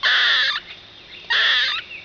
Sounds of the Squirrel
Grey Squirrel Call 2
squirrel2.wav